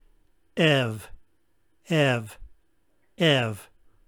In the Koine Greek era the Upsilon shifted to a consonantal \v\ sound in the vowel combinations αυ <